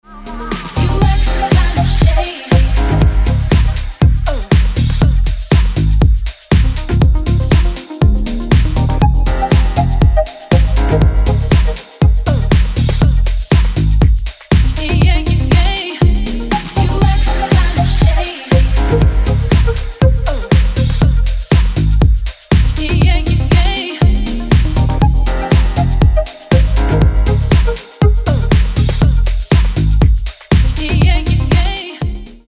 • Качество: 320, Stereo
красивые
женский вокал
deep house
dance
спокойные
club
приятные